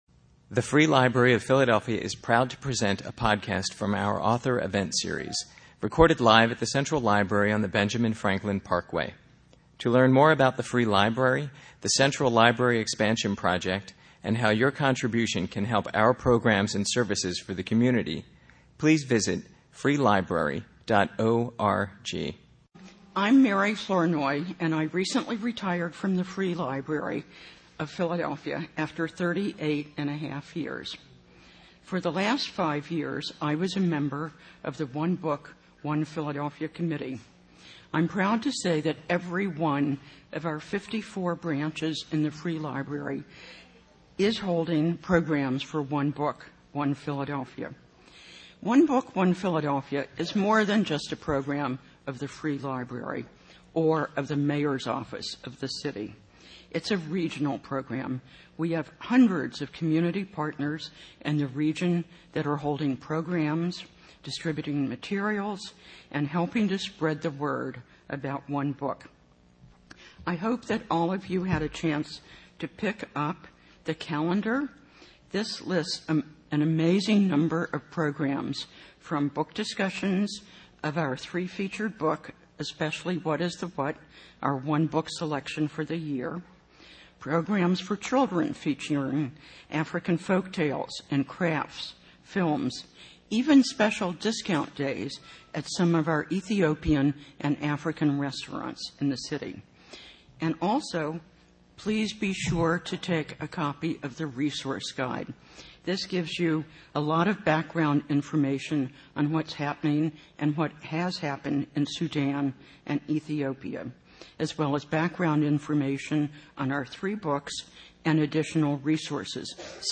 The Free Library of Philadelphia has podcasts of authors talking about their books.